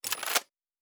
pgs/Assets/Audio/Sci-Fi Sounds/Weapons/Weapon 01 Reload 1.wav at master
Weapon 01 Reload 1.wav